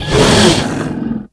c_croccata_hit3.wav